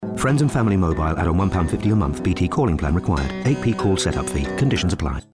Endline, Tagline, Fast Read,Legal
Straight